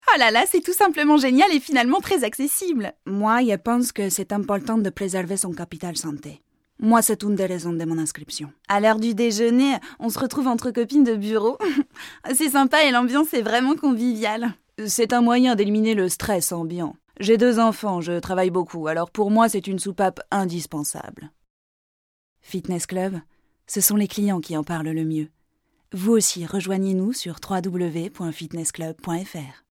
comédienne 30 ans, voix médium.
Sprechprobe: Sonstiges (Muttersprache):